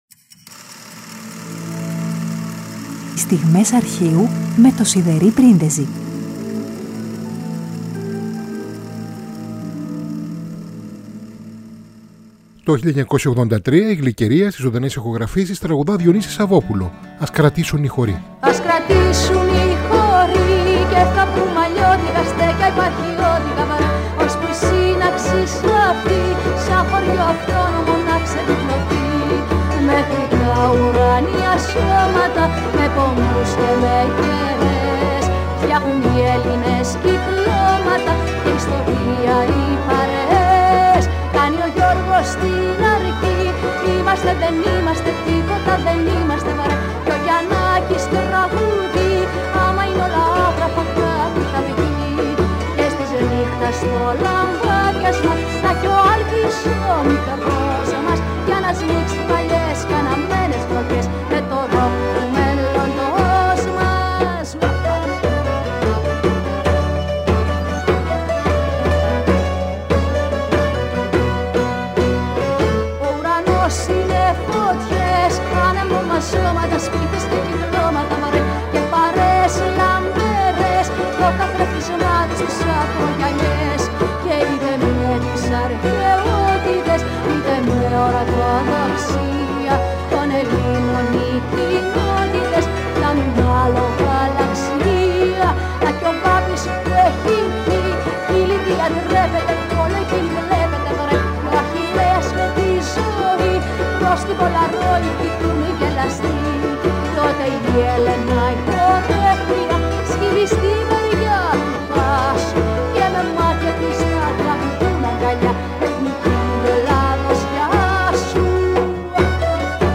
τραγούδια ηχογραφημένα στο ραδιόφωνο